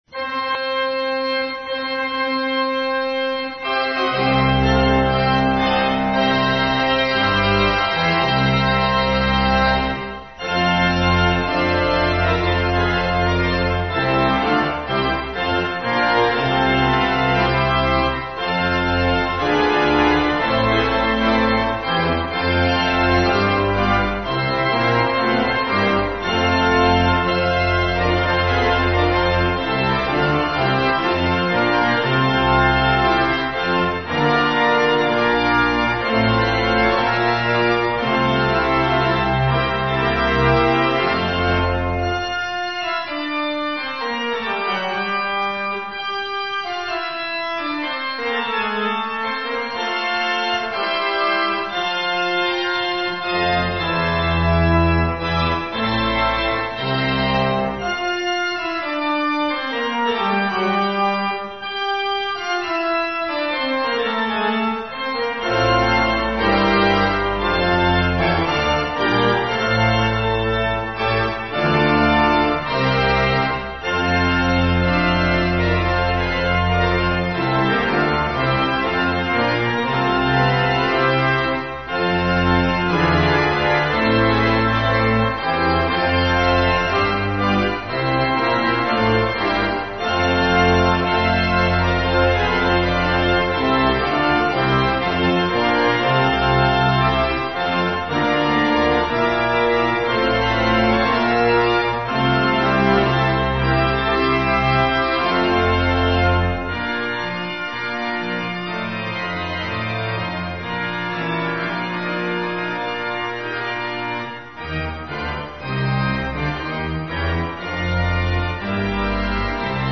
St Georges Church Organ